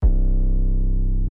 (BASS) Delay.wav